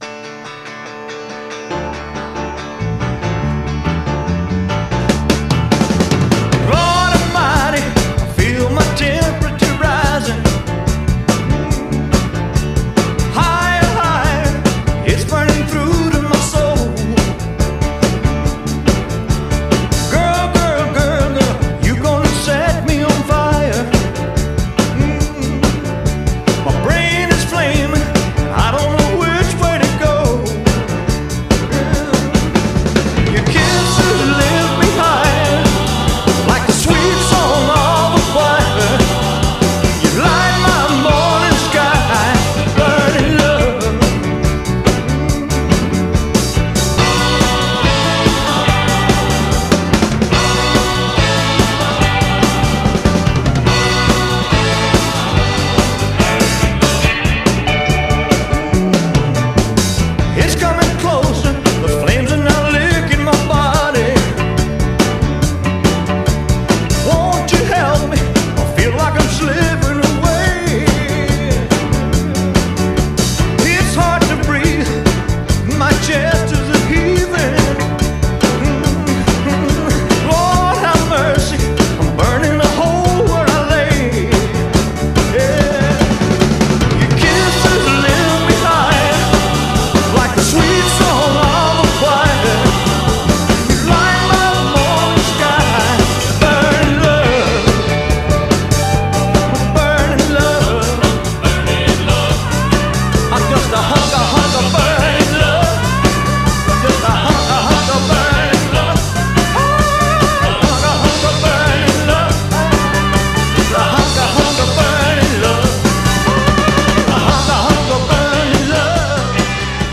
BPM140-147
Audio QualityMusic Cut